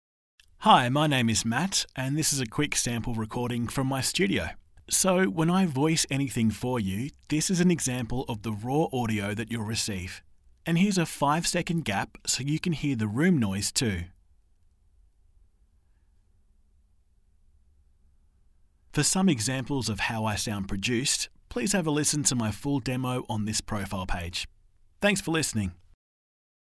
Young and fun, sophisticated and natural, pulled back and versatile….  he’s good to go when you’re ready!
• Studio Sound Check
• Upbeat